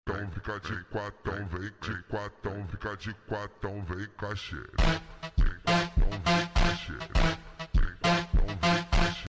You Just Search Sound Effects And Download. tiktok hahaha sound effect Download Sound Effect Home